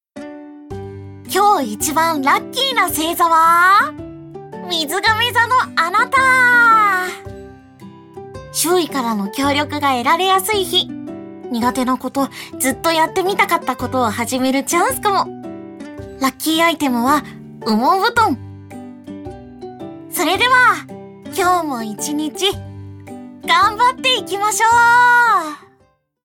ボイスサンプル
(キャラクターボイスで朝ver.)